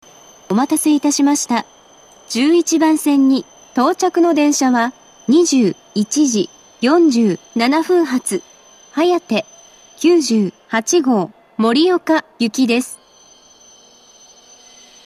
１１番線到着放送
接近放送及び到着放送は「はやて９８号　盛岡行」です。